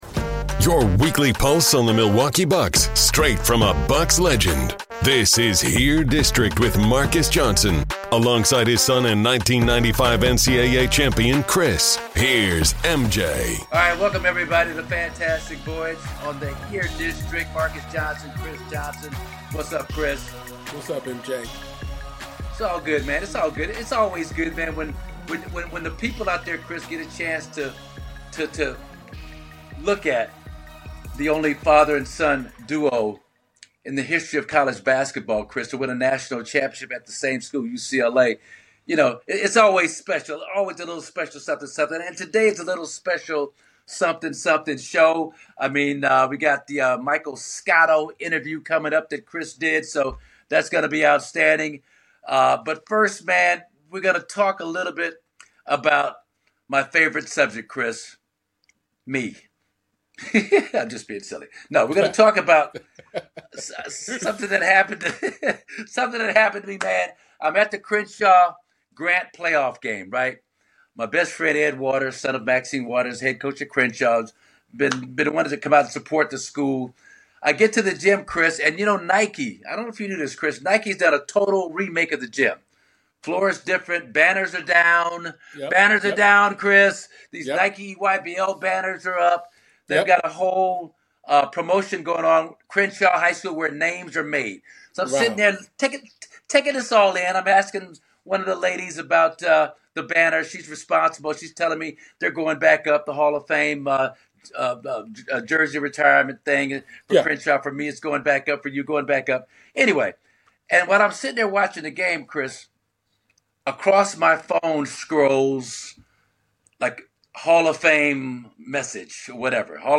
The episode features powerful perspectives from NBA legends, including Dwyane Wade, Paul Pierce, Kenyon Martin, and Brandon Jennings, as MJ reflects on legacy, résumé comparisons, and what Hall of Fame recognition truly means.